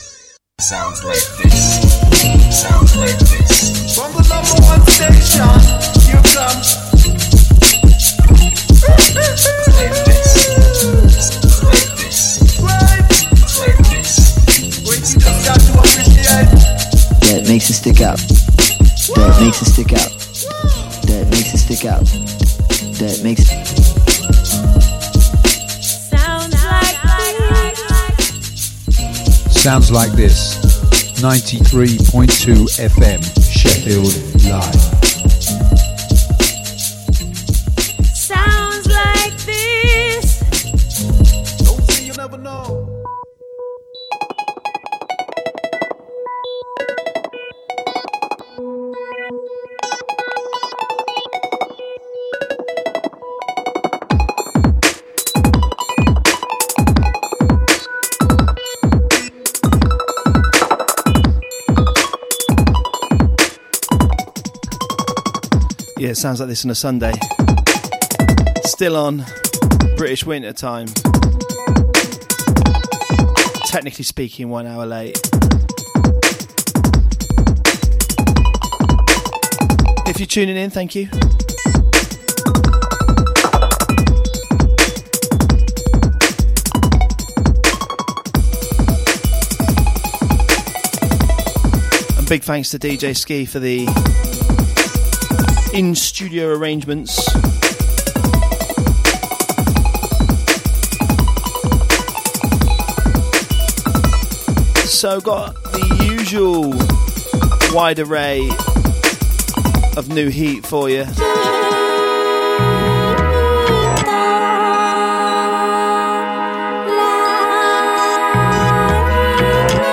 Roastin some leftfield dance type music!